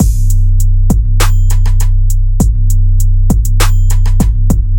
100 BPM Trap Drum Loop
描述：Trap 808 Beat